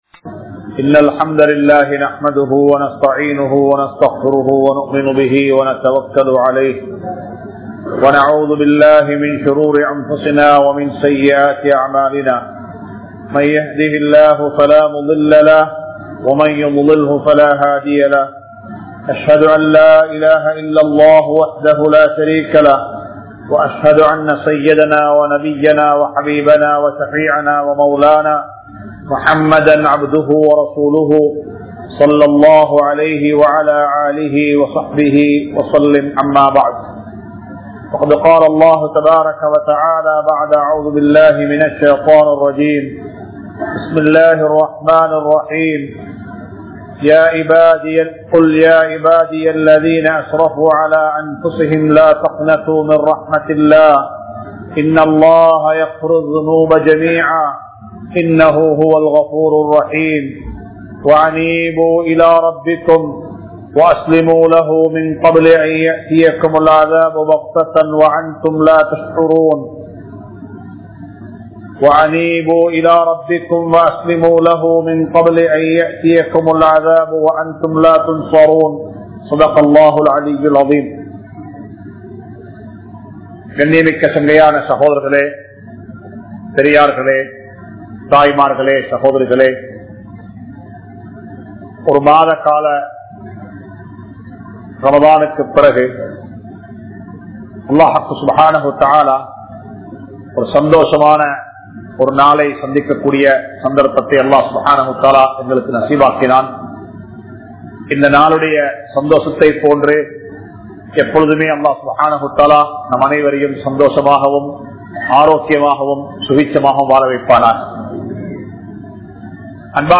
Eid Ul Fithr Bayan | Audio Bayans | All Ceylon Muslim Youth Community | Addalaichenai
Muhiyadeen Jumua Masjith